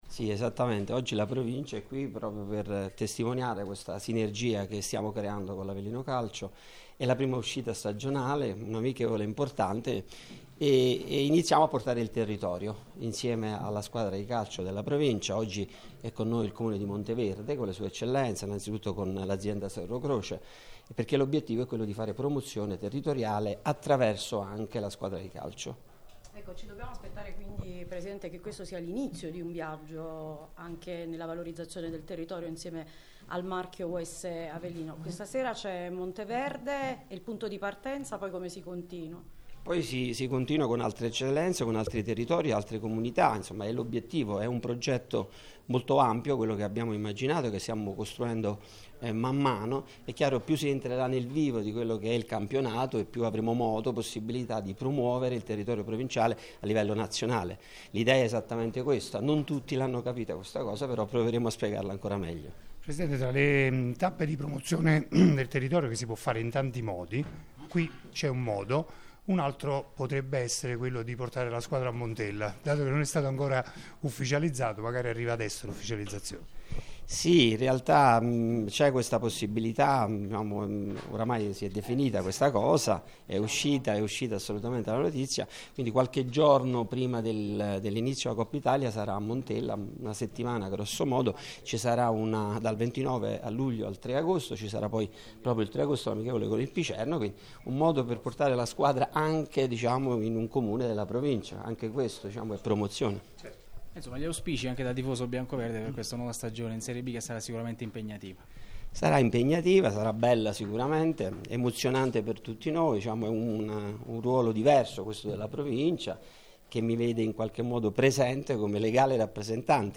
Alla vigilia della prima amichevole pre season della stagione 2025-26 dell’Avellino, in quel di Rivisondoli ha parlato Rizieri Buonopane. Grande la soddisfazione del Presidente della Provincia per la partnership con la società biancoverde che ha come scopo principale quello di esportare le bellezze dell’Irpinia in tutta Italia.
ASCOLTA LE PAROLE DEL PRESIDENTE RIZIERI BUONOPANE